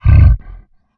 CapersProject/MONSTER_Grunt_Breath_05_mono.wav at d0a8d3fa7feee342666ec94fc4a0569fb8c8c2c9
MONSTER_Grunt_Breath_05_mono.wav